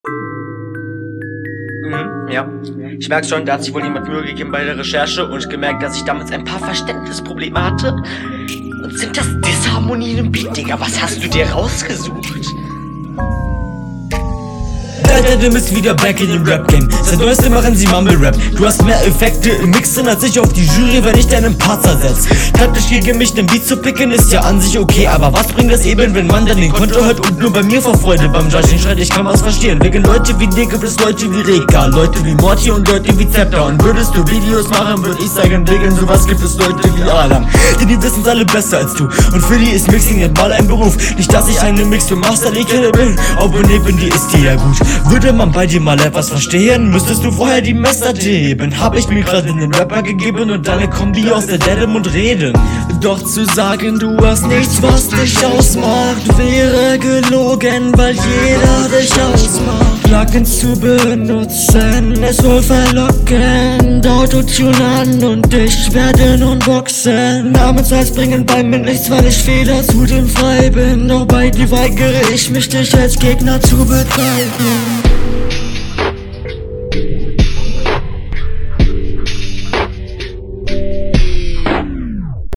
Flow: der flow ist wieder oke, nur halt ziemlich eintönig.